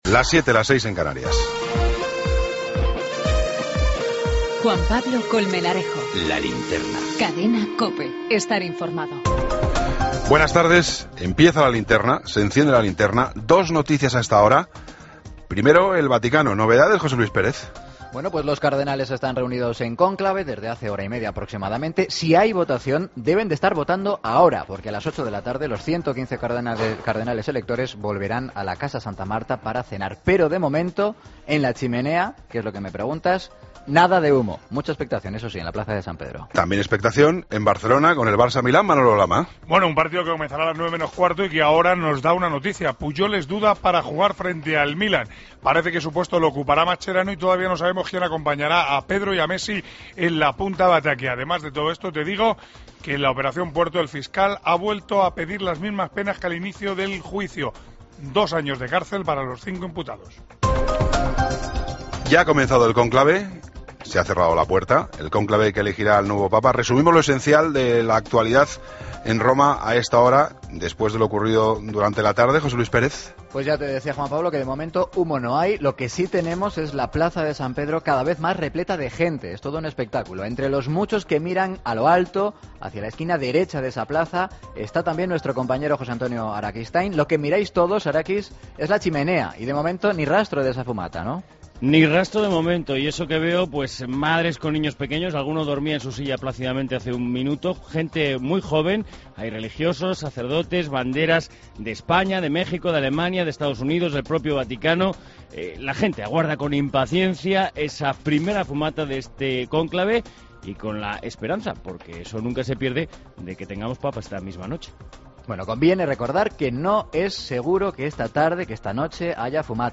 AUDIO: Toda la información con Juan Pablo Colmenarejo.
Deportes con Manolo Lama.